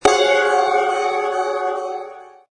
Descarga de Sonidos mp3 Gratis: suspenso 20.